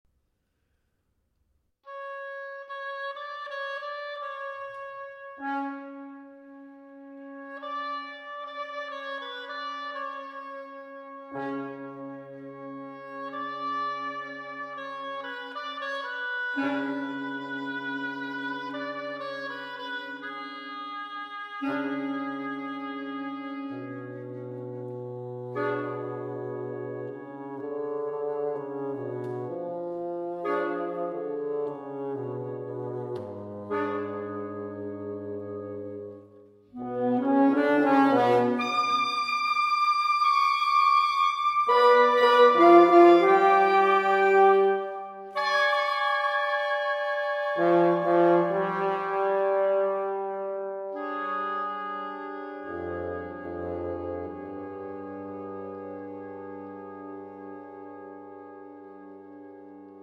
Rubato, Lamentoso   3:01